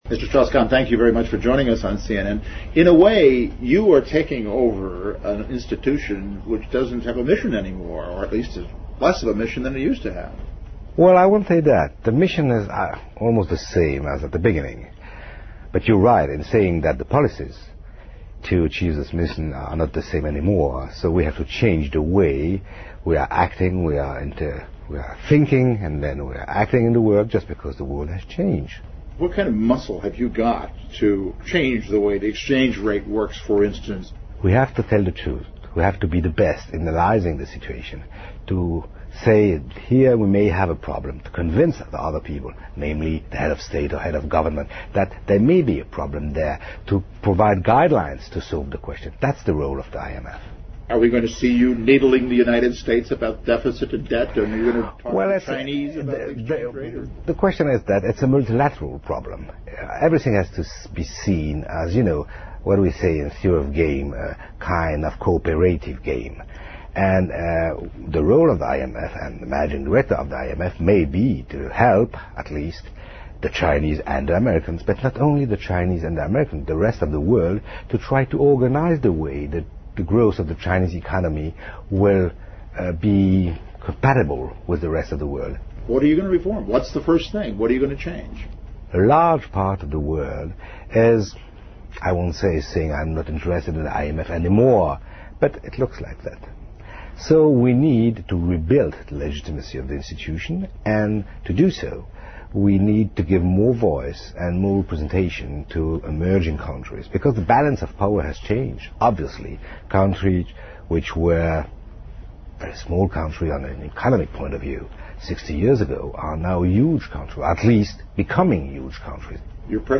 Business Channel 2007-10-04&10-06, 专访国际货币基金组织主席 听力文件下载—在线英语听力室